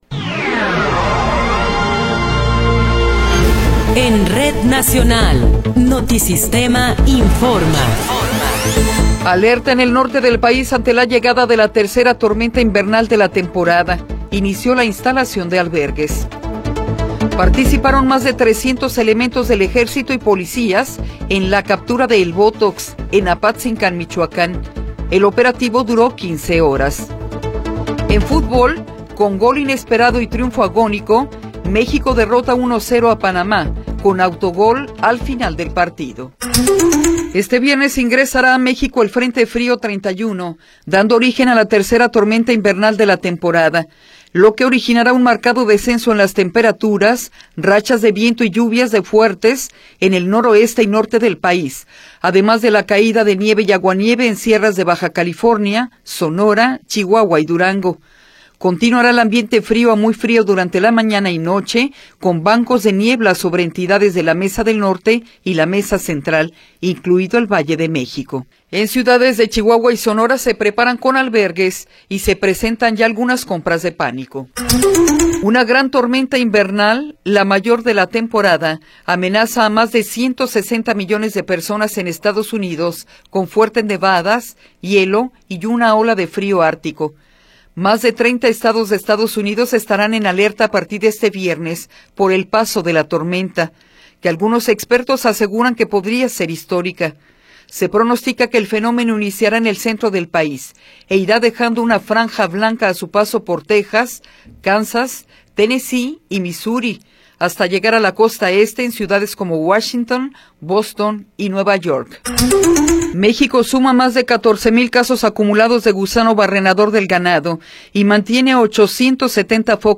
Noticiero 8 hrs. – 23 de Enero de 2026